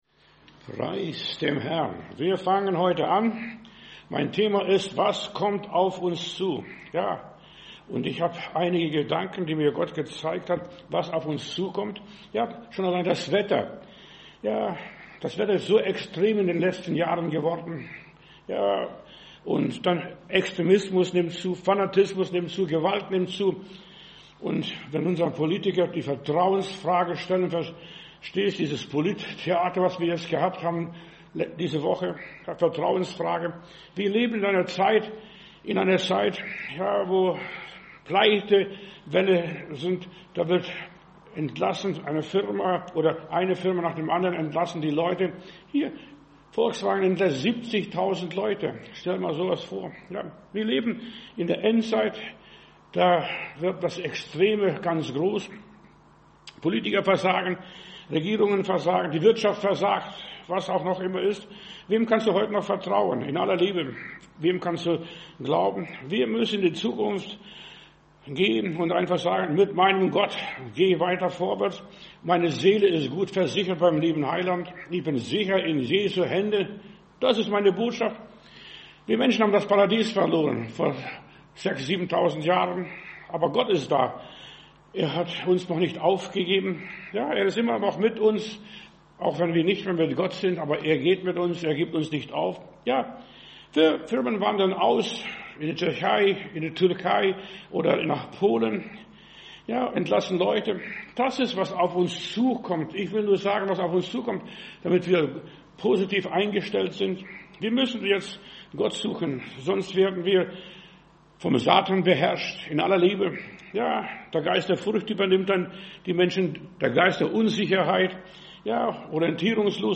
Predigt herunterladen: Audio 2024-12-18 Was kommt auf uns zu?